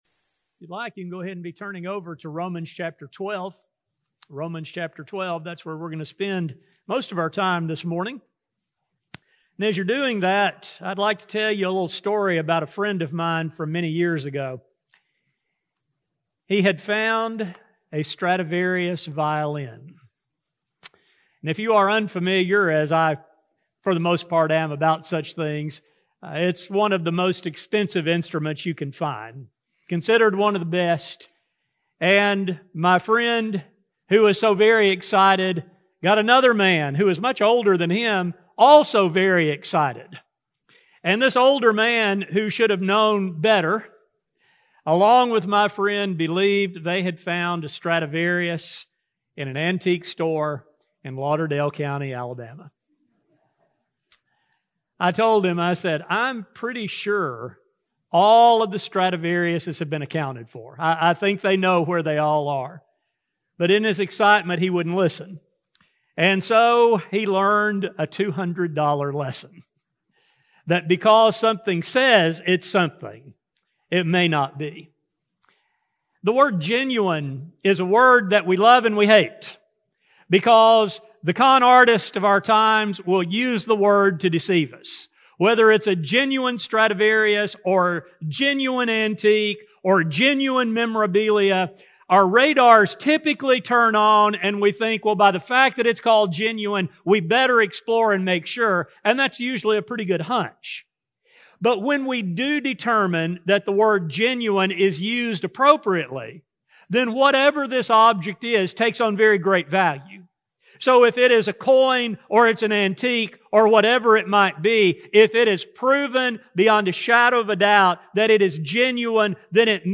This study focuses on the need for pure love for fellow members of the body of Christ. A sermon recording